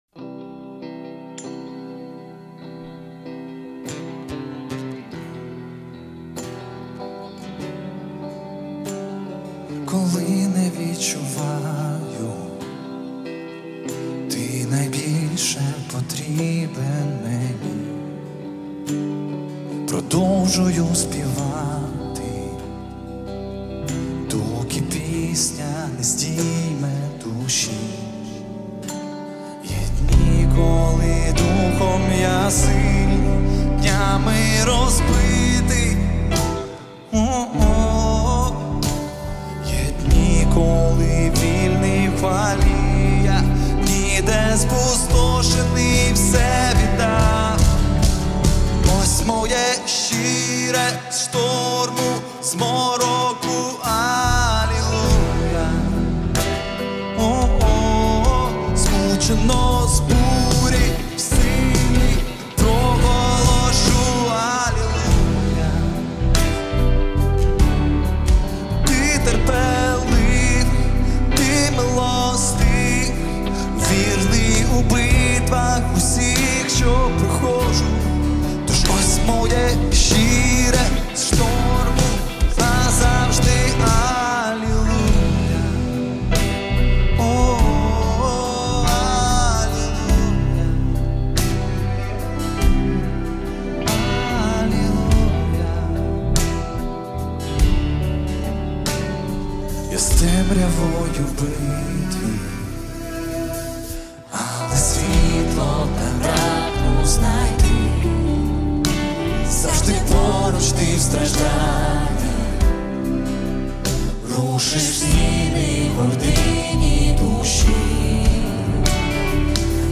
1305 просмотров 516 прослушиваний 53 скачивания BPM: 144